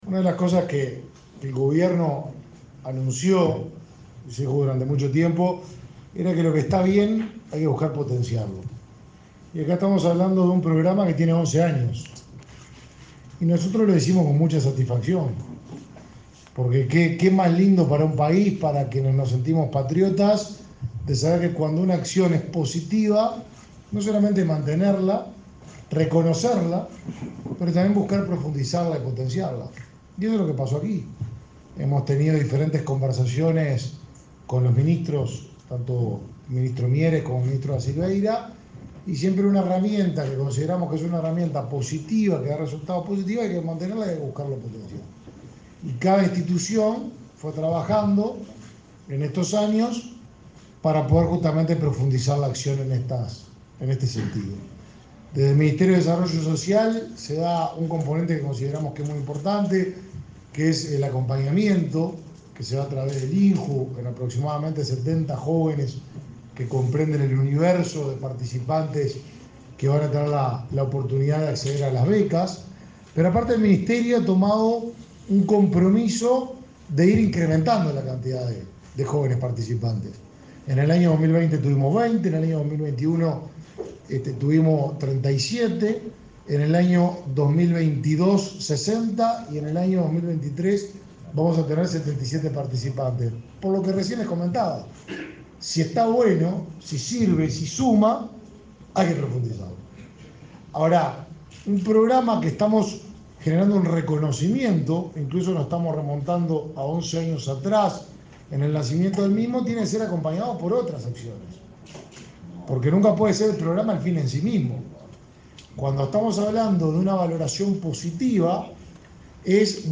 Palabras del ministro de Desarrollo Social, Martín Lema
Este miércoles 26, el ministro de Desarrollo Social, Martín Lema, participó en la presentación de la 11.ª edición del programa Yo Estudio y Trabajo.